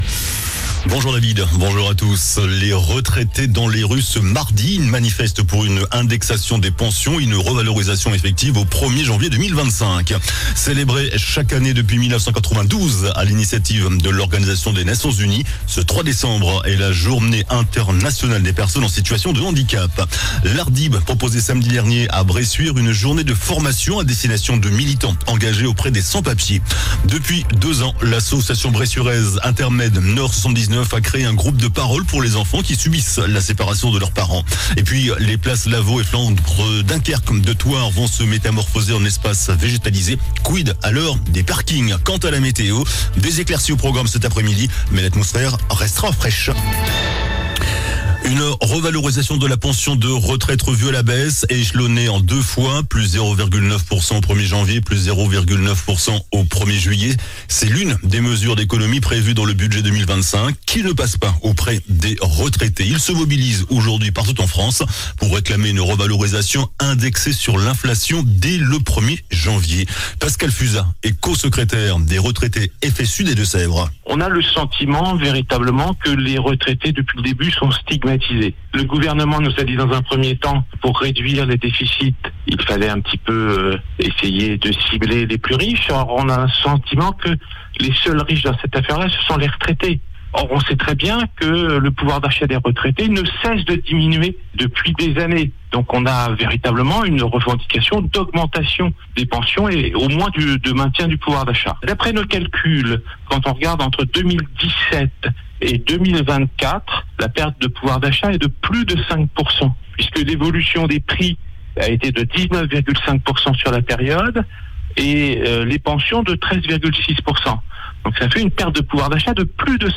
JOURNAL DU MARDI 03 DECEMBRE ( MIDI )